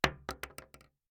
shotgun_wood_1.ogg